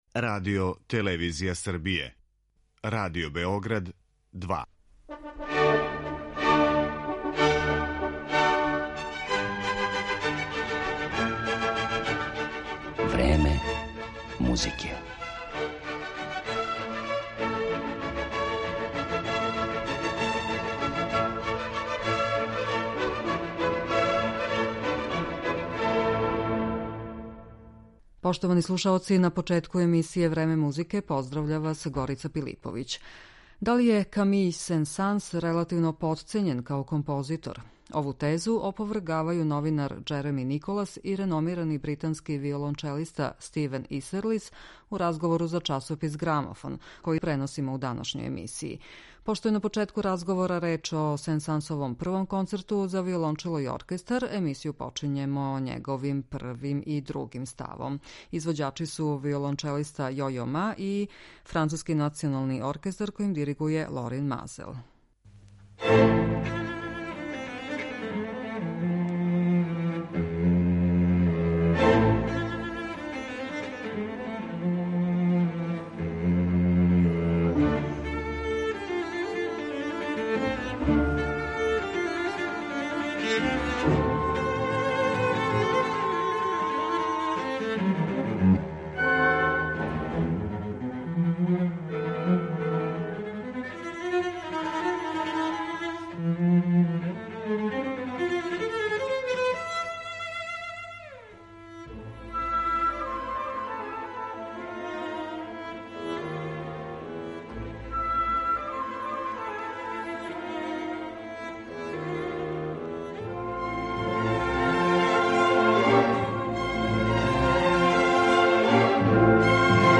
У емисији Време музике преносимо разговор